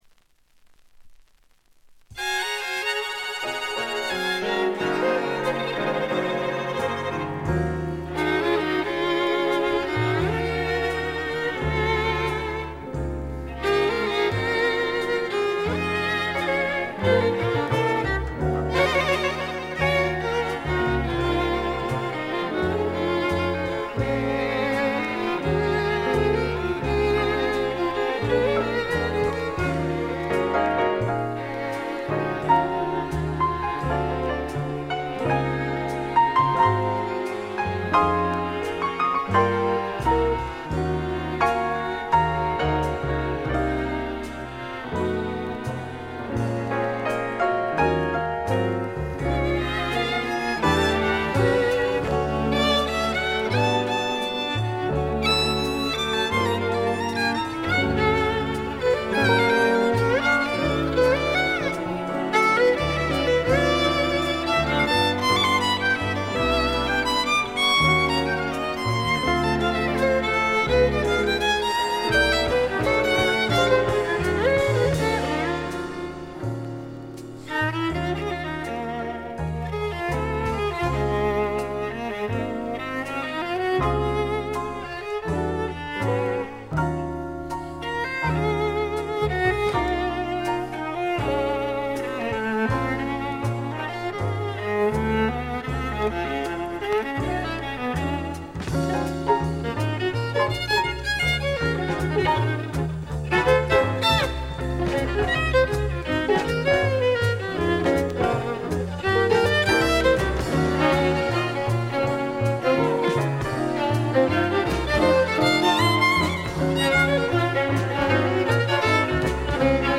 部分試聴ですがほとんどノイズ感無し。
エリア・コード615等で活躍した名フィドラ-。
試聴曲は現品からの取り込み音源です。
Violin, Viola, Mandola